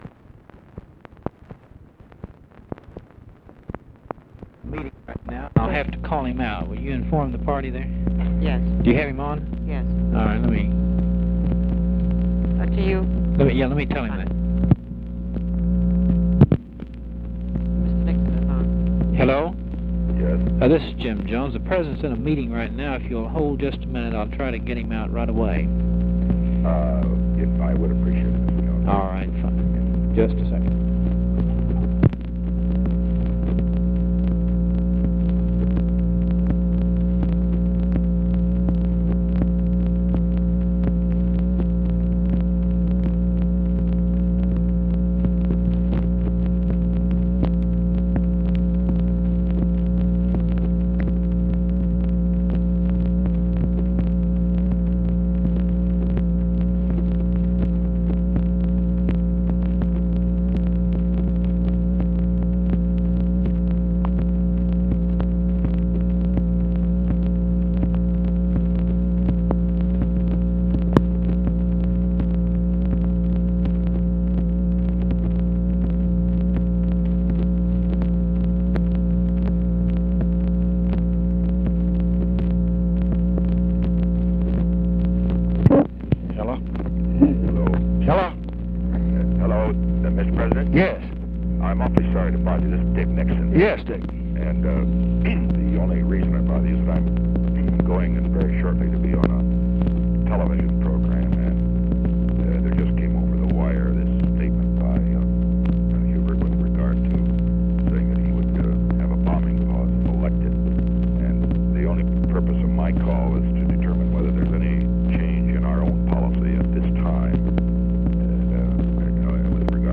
Conversation with RICHARD NIXON, TELEPHONE OPERATOR, JIM JONES and WALT ROSTOW, September 30, 1968
Secret White House Tapes